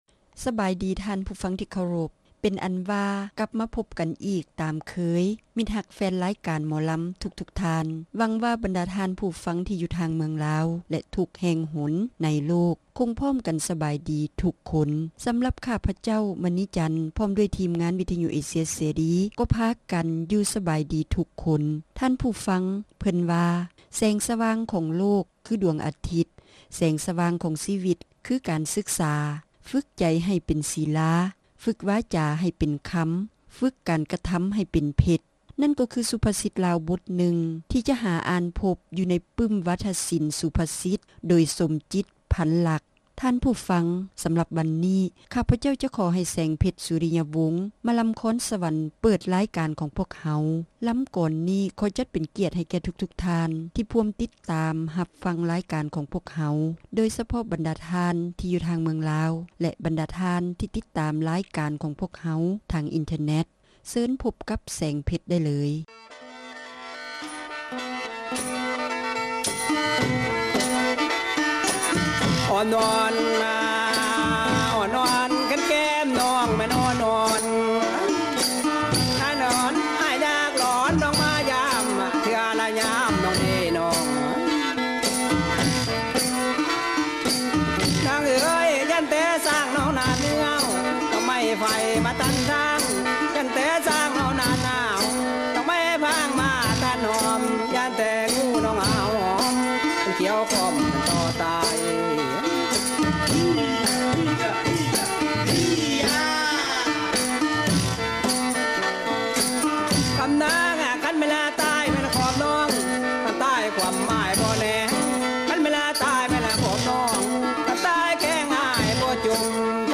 ຣາຍການໜໍລຳ